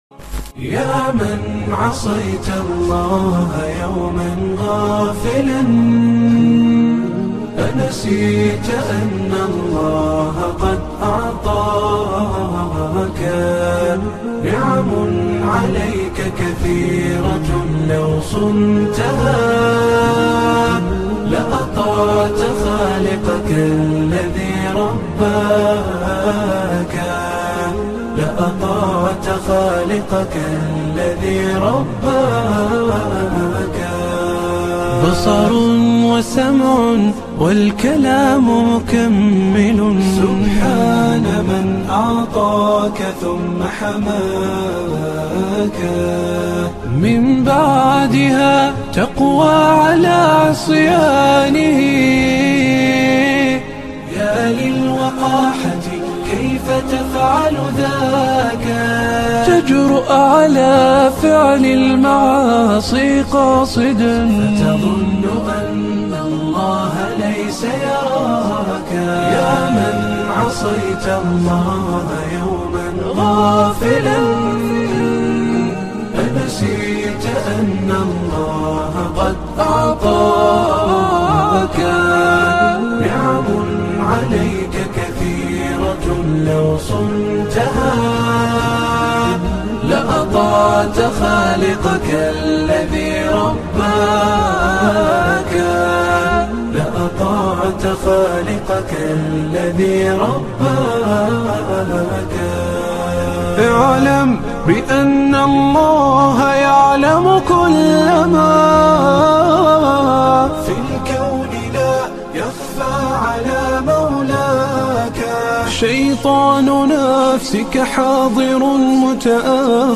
انشودة